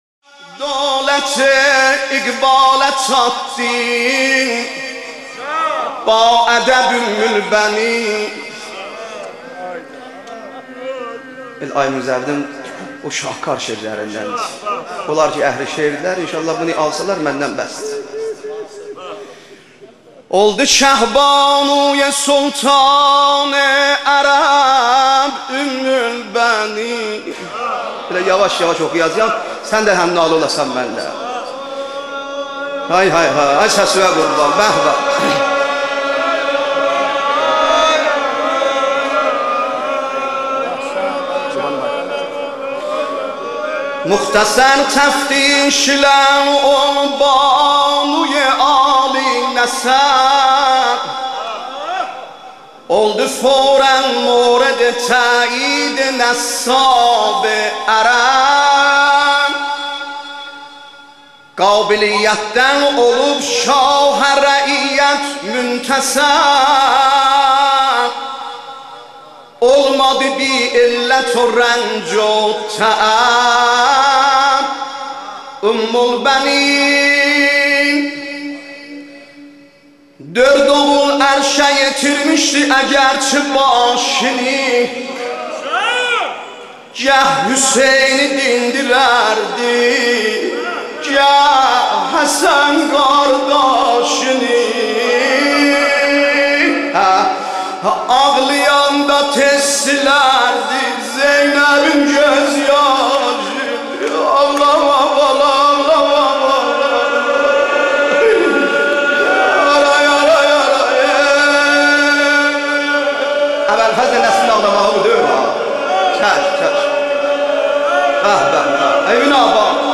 مداحی آذری نوحه ترکی